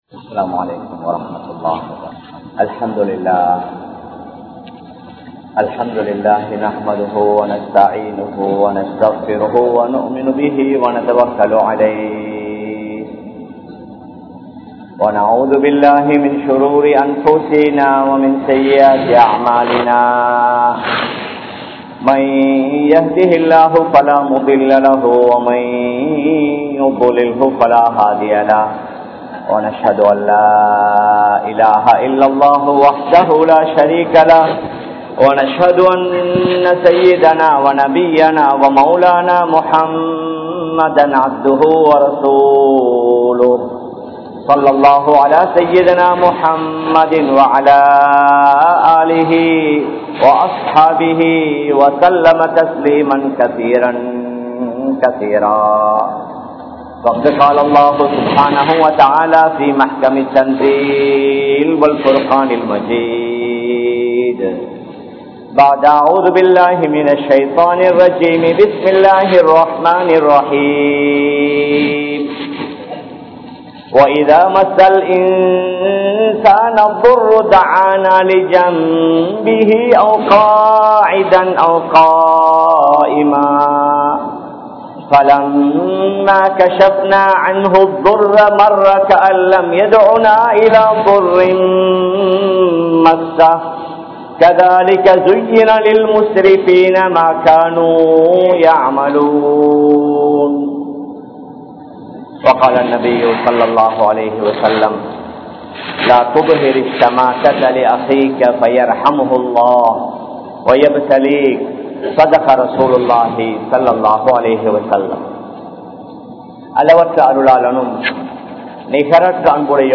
Vaalvum Maranamum Ethatku? (வாழ்வும் மரணமும் எதற்கு?) | Audio Bayans | All Ceylon Muslim Youth Community | Addalaichenai
Minnan Jumua Masjith